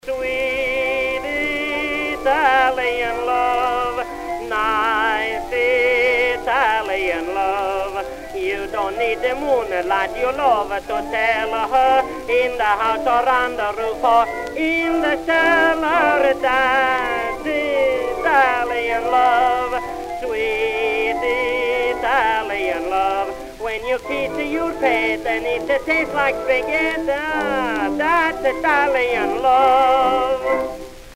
Orch. acc.